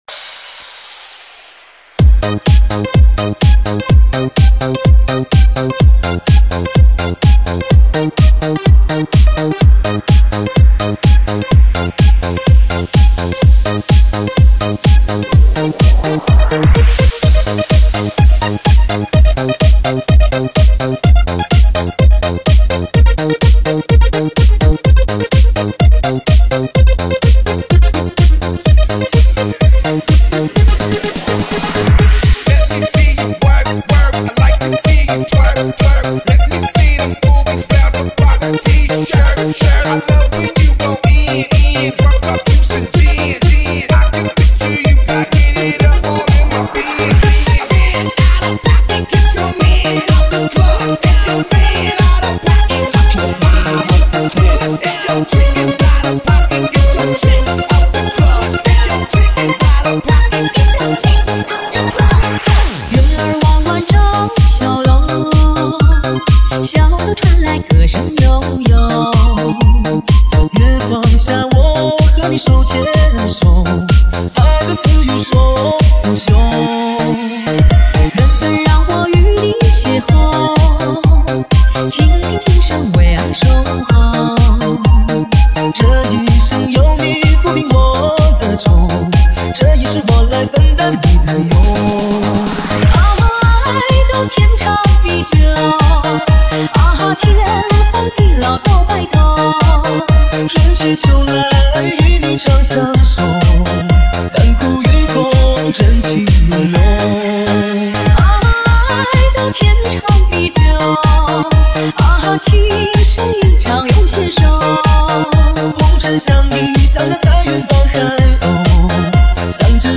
唱片类型：电音炫音
13首独家舞曲版，国际潮流与国内流行的
低品质试听